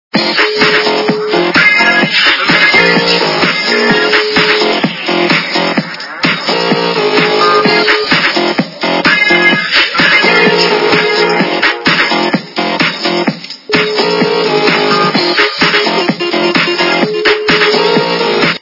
западная эстрада
качество понижено и присутствуют гудки